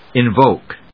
/ɪnvóʊk(米国英語), ɪnvˈəʊk(英国英語)/